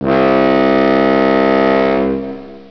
Tug Boat Horns
Ocean-going Tug horn